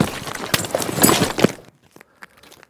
Ledge Climbing Sounds Redone